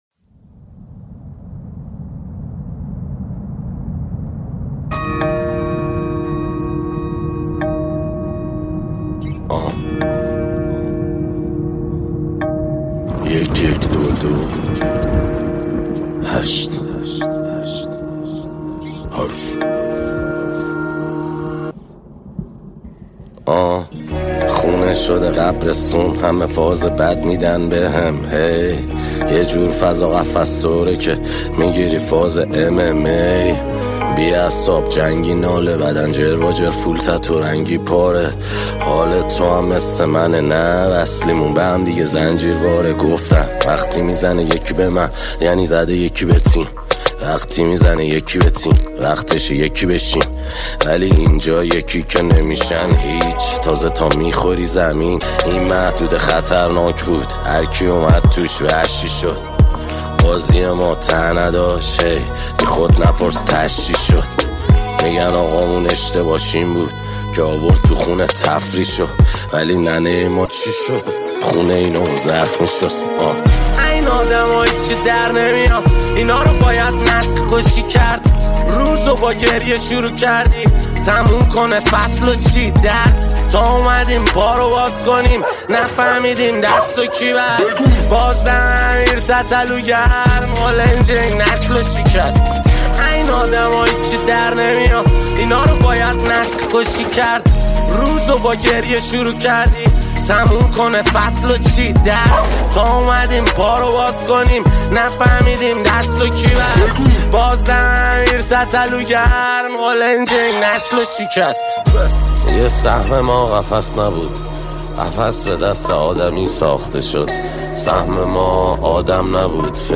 موزیک و اصلاح اهنگ های رپ